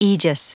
Prononciation du mot aegis en anglais (fichier audio)
Prononciation du mot : aegis